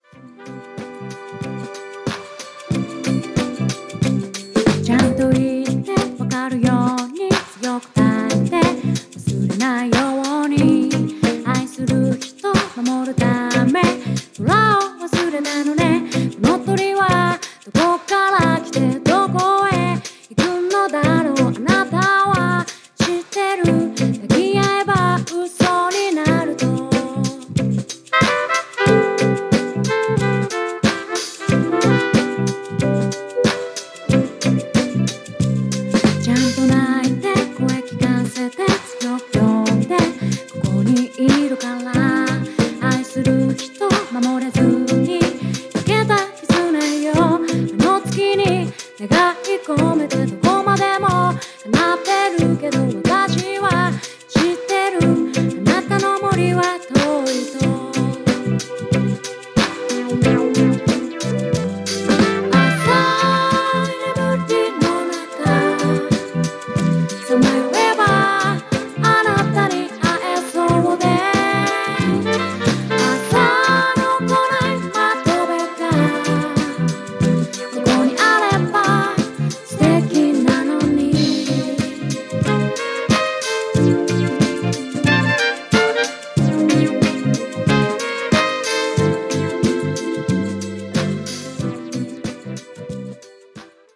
癒されますね～。